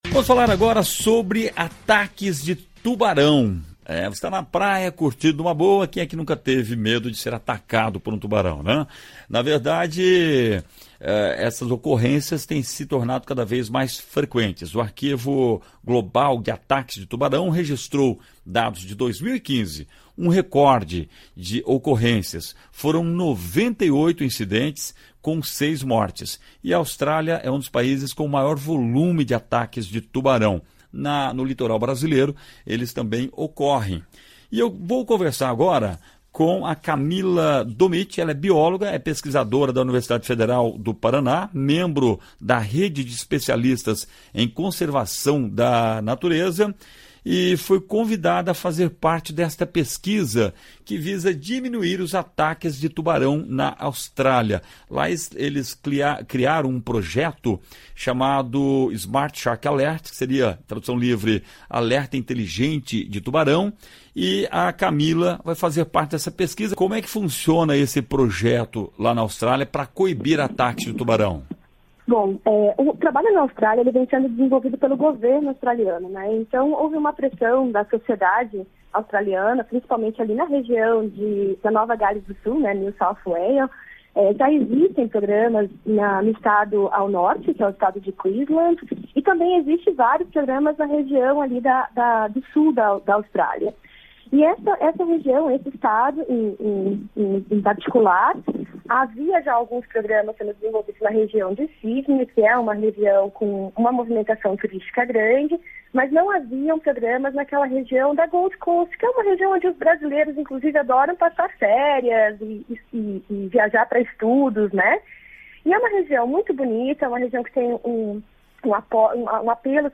Entrevista: Conheça o projeto australiano contra ataques de tubarão que deve ser copiado no Brasil